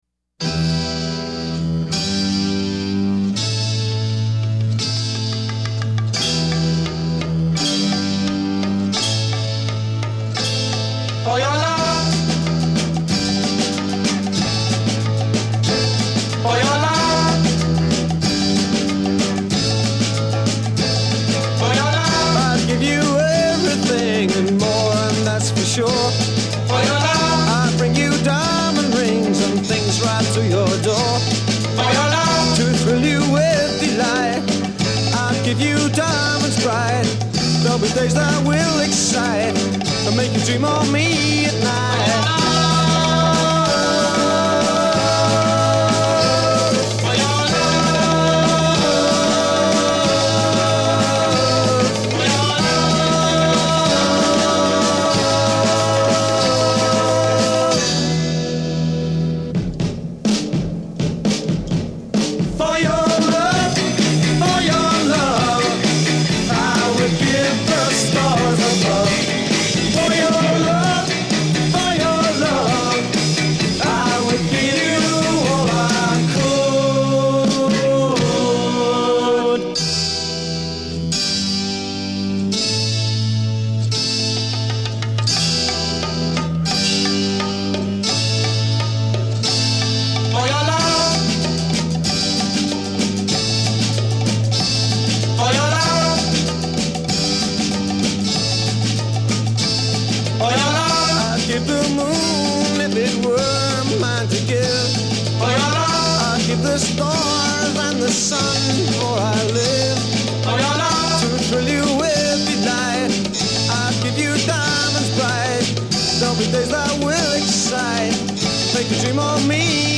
Recorded at Olympic Sound Studios [?]
Intro 0:00 8 harpsichord (one chord/measure)
Chorus : 4 drum segue
: 18 vocal ensemble c
Verse Part A : 8 harpsichord & band w/ chorus a